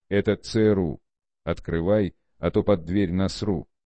На этой странице собраны звуки, связанные с работой ФБР: перехваты переговоров, сигналы спецоборудования, тревожные гудки и другие эффекты.
Стук ФБР при взломе